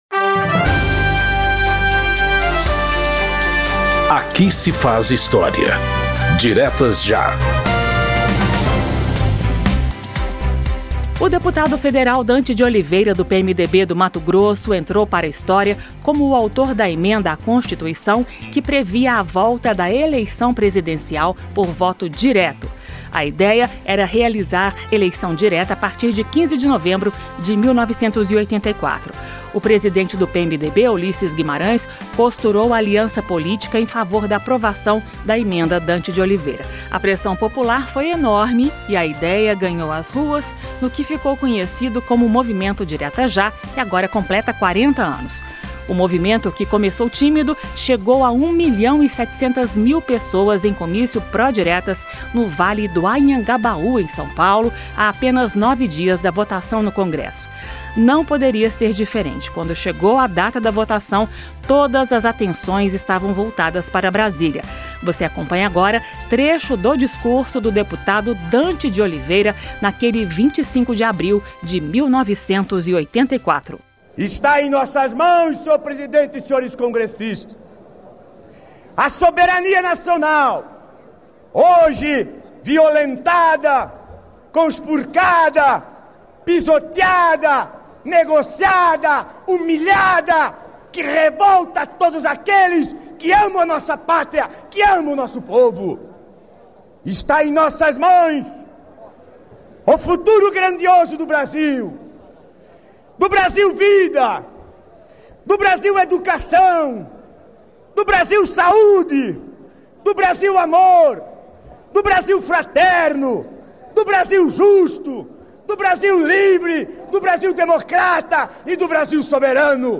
Um programa da Rádio Câmara que recupera pronunciamentos históricos feitos no Parlamento por deputados ou agentes públicos, contextualizando o momento político que motivou o discurso.
pgm-aqui-se-faz-histria-especial-40-anos-diretas-ja-discurso-dante-de-oliveira-ed-003.mp3